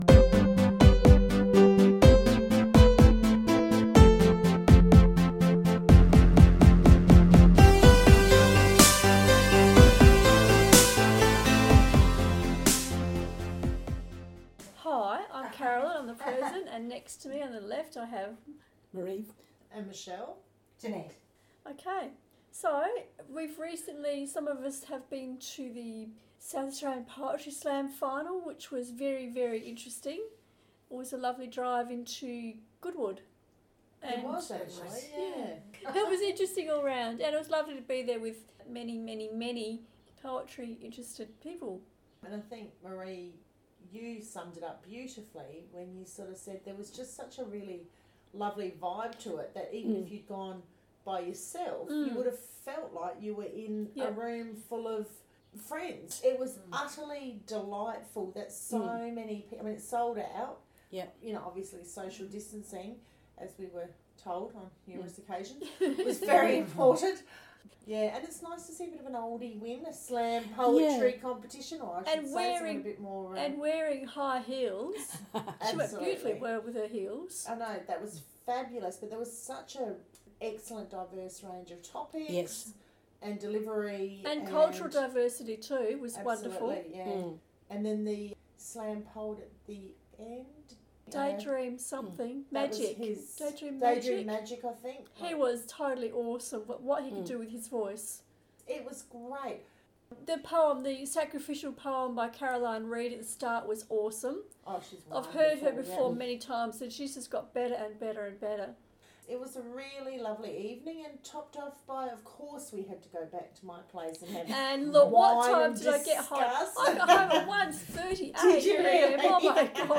2020 Adelaide Plains Chapter and Verse monthly podcast recorded Wednesday 21 st October, Gawler South.
There is a lively discussion about books featuring family and friendship, an argument (almost) about the importance or otherwise of detail in writing and reading of samples of own works poetry and prose. The word of the month is “flimp”.